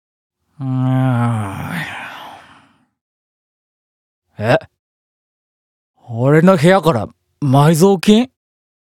Chat Voice Files
Speaker Mammon
Sleep Talking